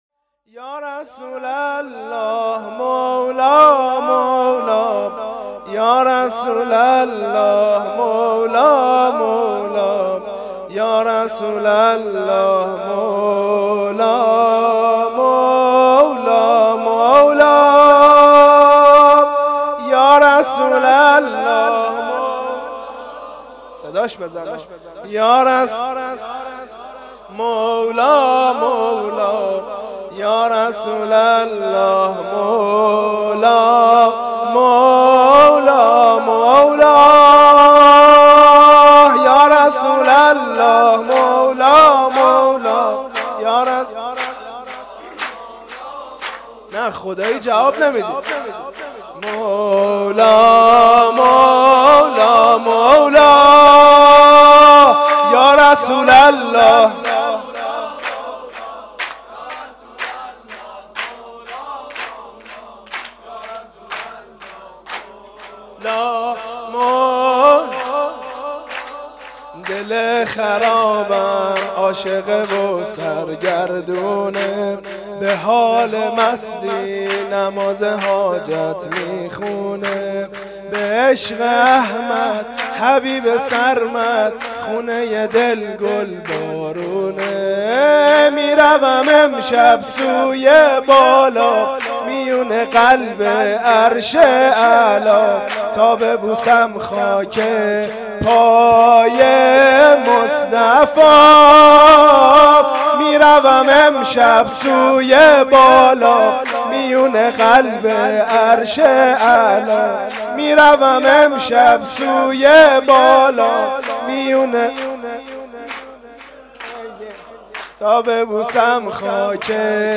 جشن مبعث پیامبر اعظم (ص)؛ سرود